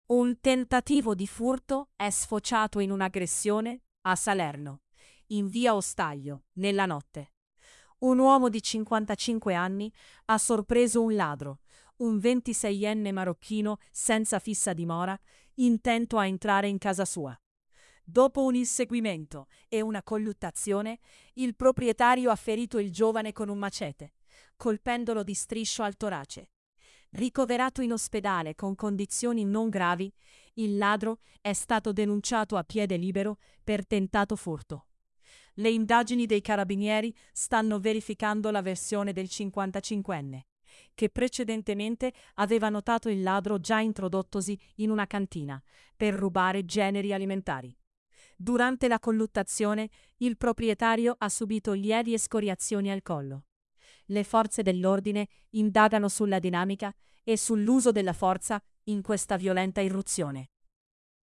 salerno-scopre-il-ladro-in-casa-e-lo-ferisce-con-un-machete-tts-1.mp3